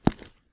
concrete_step_2_-06.wav